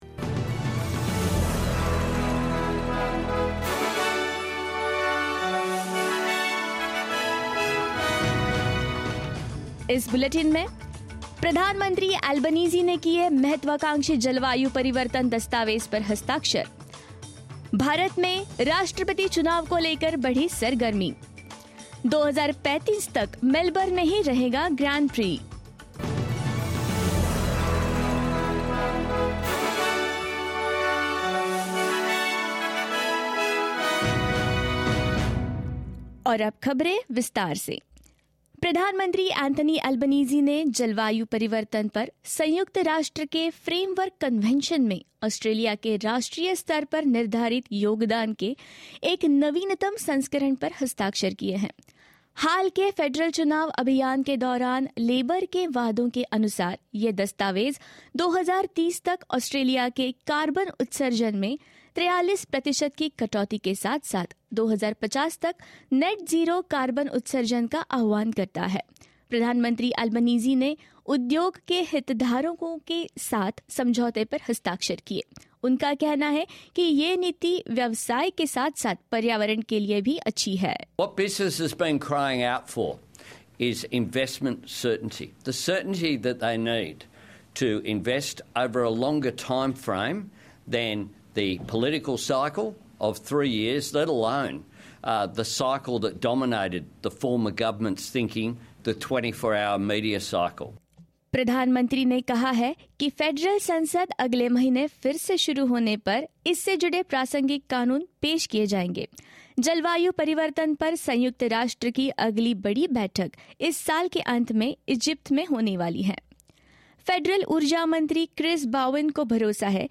In this latest SBS Hindi bulletin: The Prime Minister officially signs off on more ambitious climate change goals; Nominations process begins for India's Presidential Election 2022; Melbourne to continue hosting Grand Prix until 2035 and more